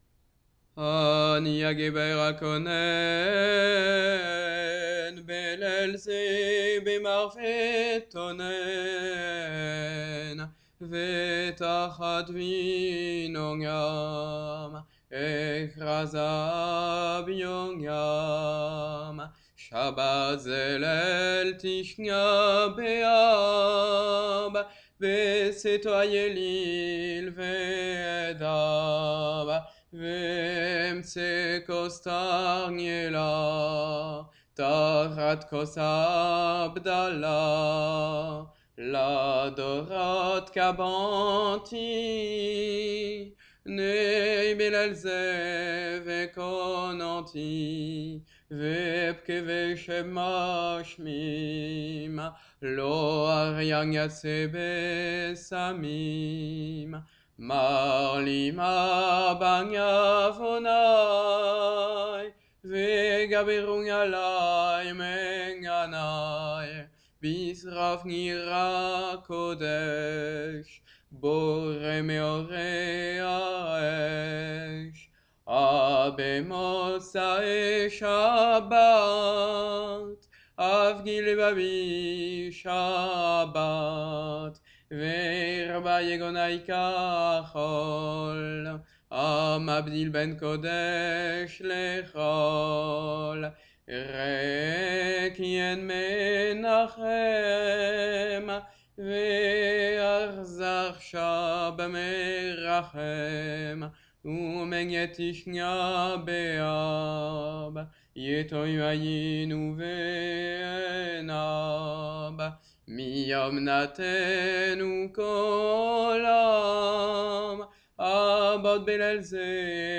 Office du soir - veille du 9 Ab :
Ani Aguéber - אני הגבר (cette élégie ne se chante qu'à la sortie de Sabbat)